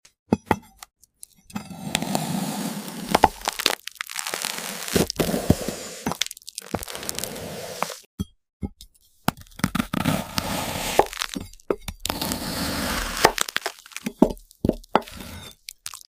Shredded coconut inside the frozen cream adds a unique crunch & texture, making this a street food favorite. The Sweet Corn Ice Cream Block sliced in ultra-realistic ASMR.